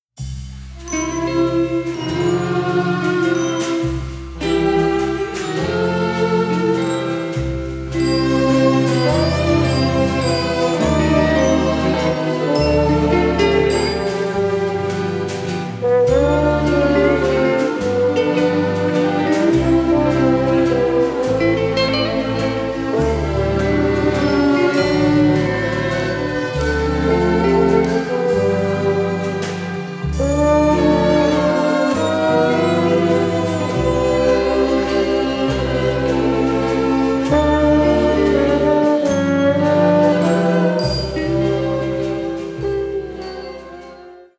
smooth slow instr.